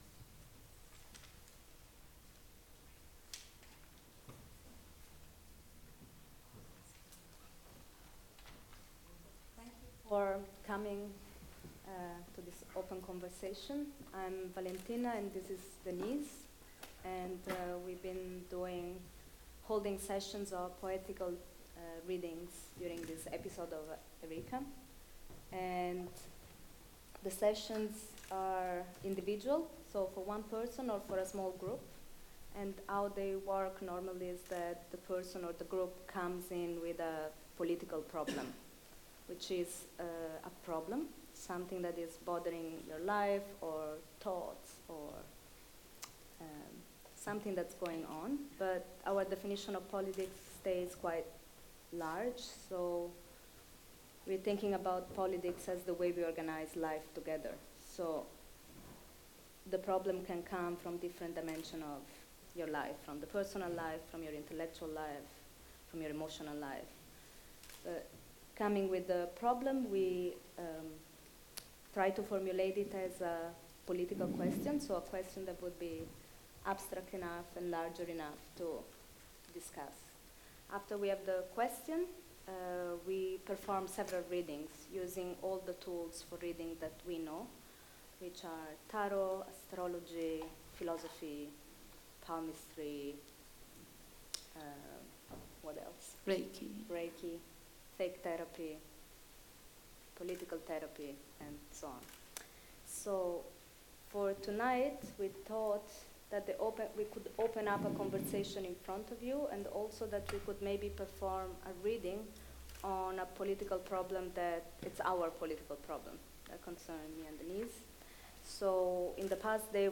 A performed, open, public conversation about how we might think politics from the position of intuition